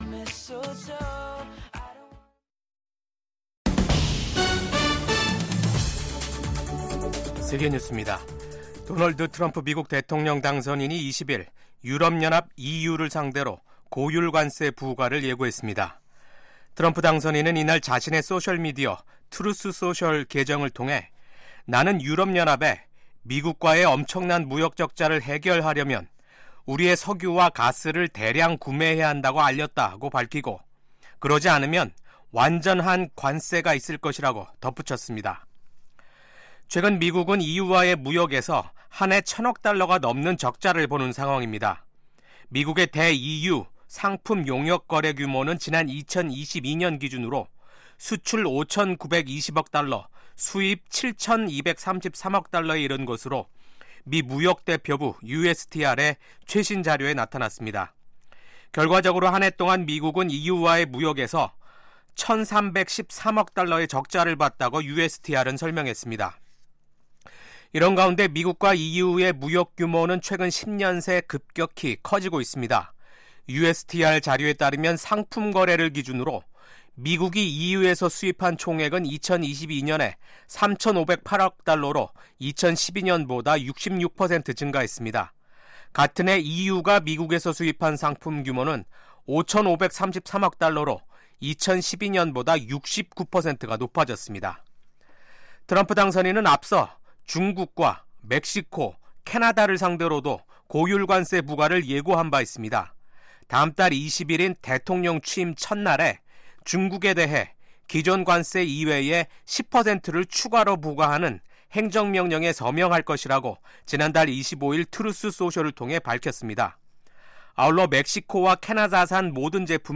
VOA 한국어 아침 뉴스 프로그램 '워싱턴 뉴스 광장'입니다. 미국 국무부는 우크라이나 전쟁에 북한군을 투입한 김정은 국무위원장의 국제형사재판소(ICC) 제소 가능성과 관련해 북한 정권의 심각한 인권 유린 실태를 비판했습니다.